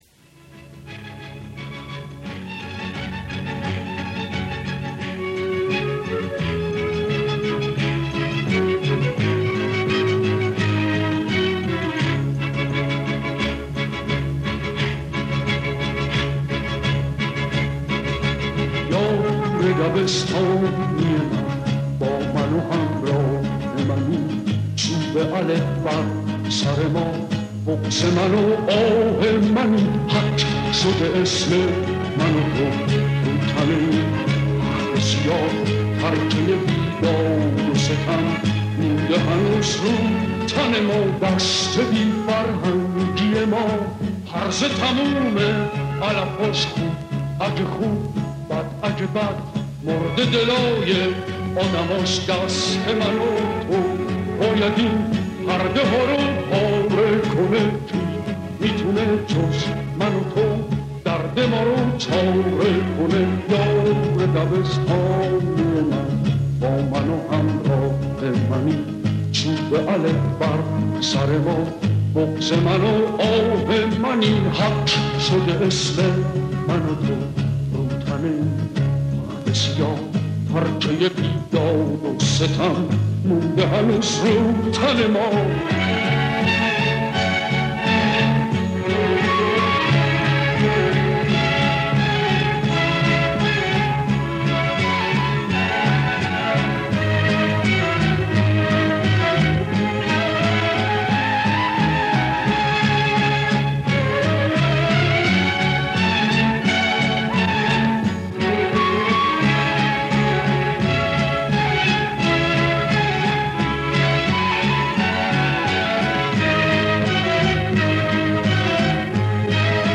آهنگ غمگین آهنگ پاپ